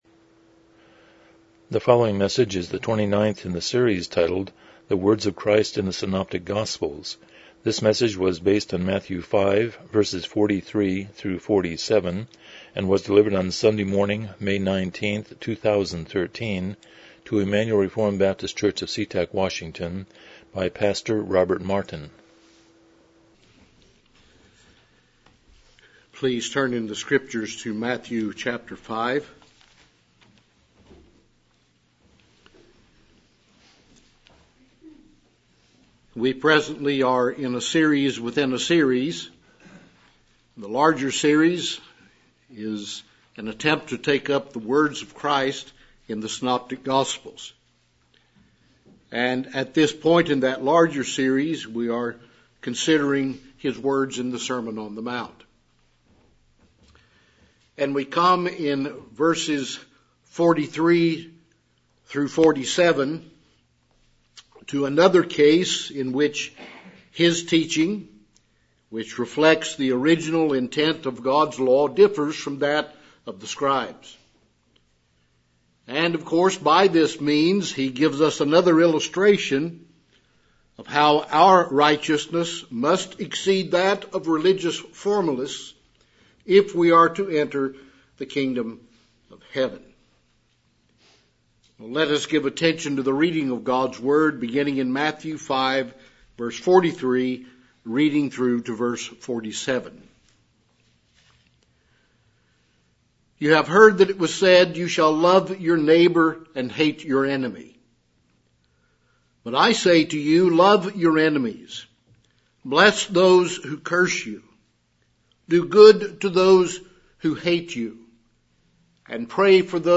Passage: Matthew 5:43-47 Service Type: Morning Worship